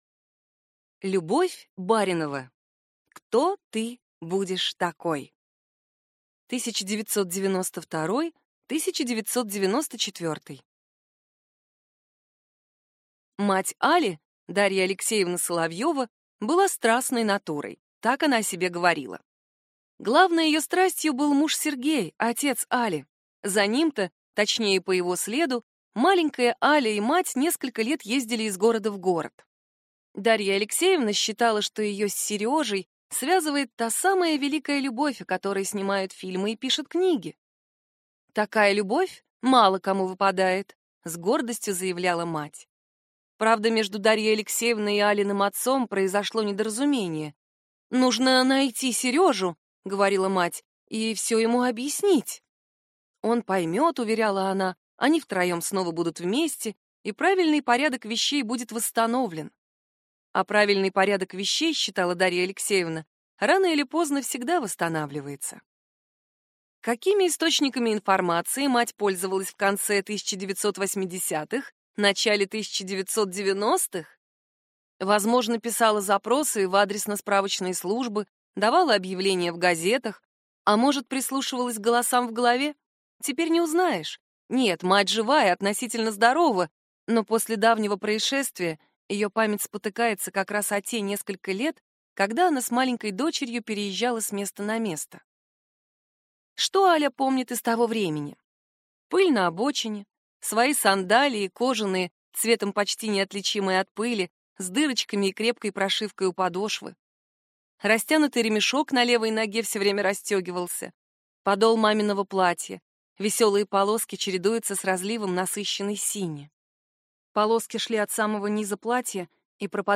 Аудиокнига Кто ты будешь такой?